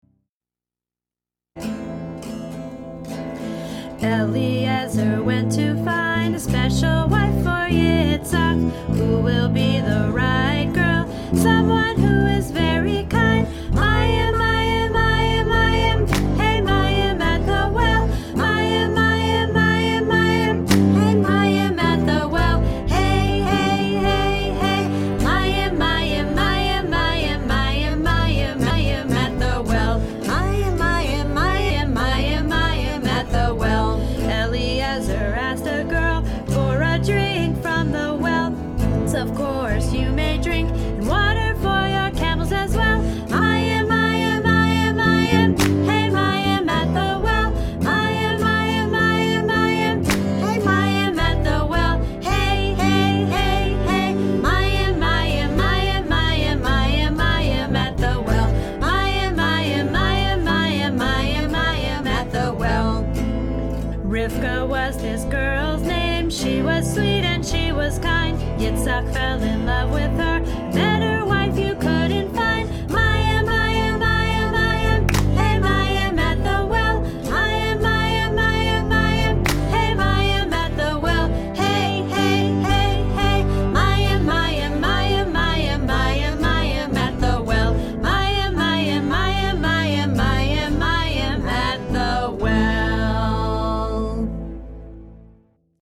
we are preschool teachers, not professional singers )